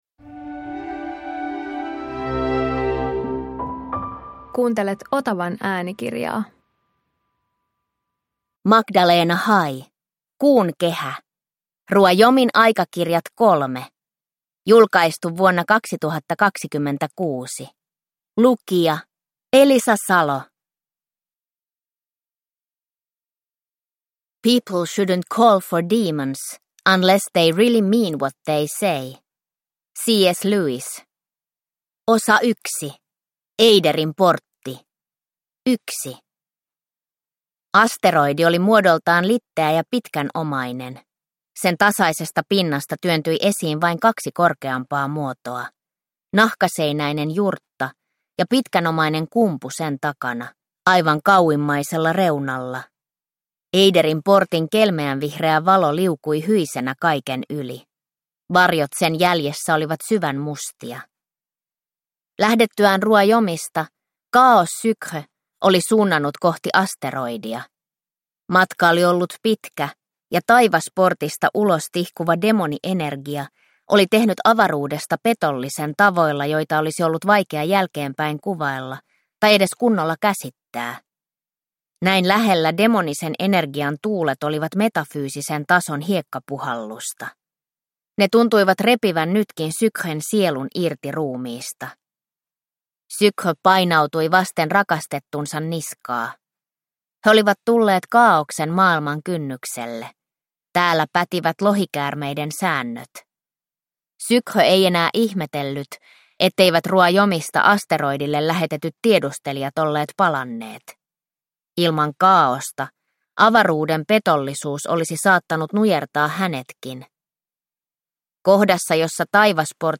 Kuunkehä – Ljudbok